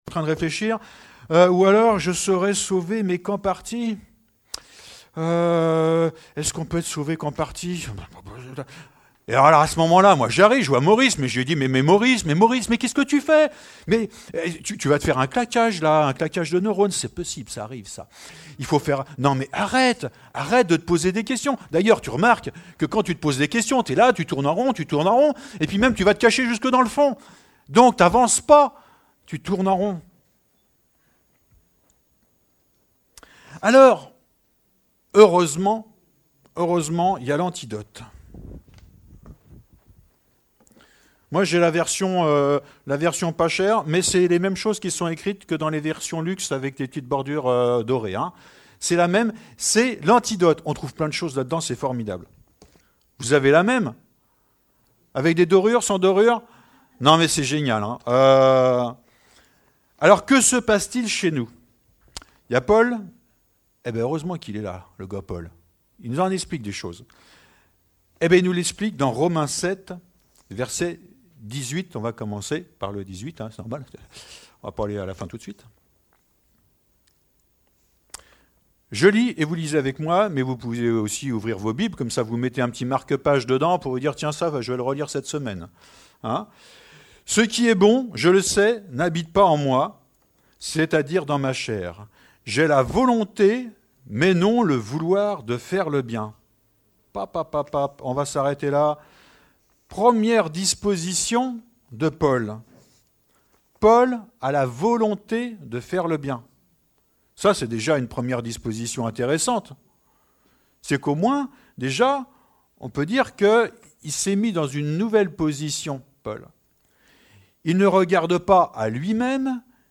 Romains 7:18 Type De Service: Culte « On ne trouvait pas de forgeron dans tout le pays d’Israël